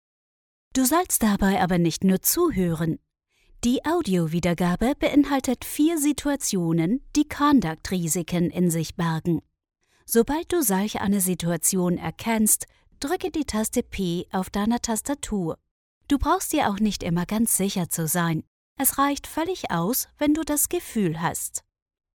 Austrian female voice overs talent   Austrian male voice overs
locutora austria, austrian german voice over